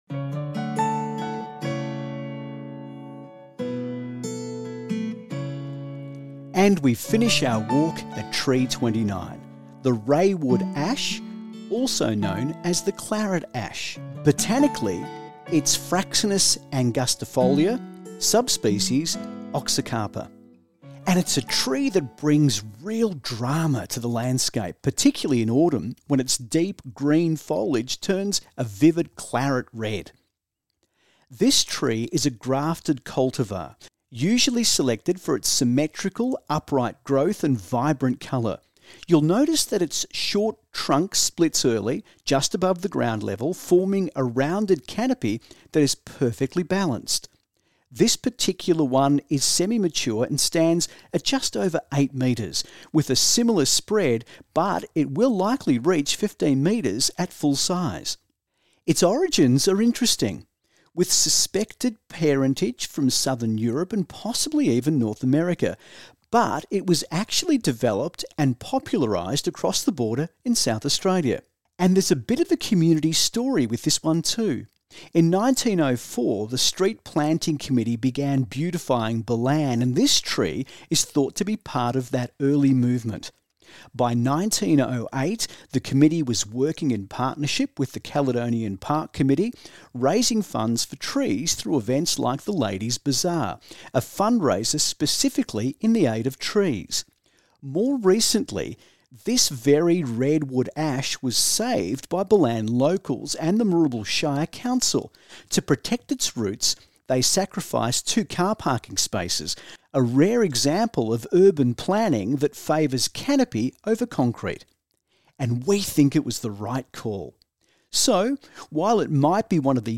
Audio Tour of the Ballan Historic Tree Walk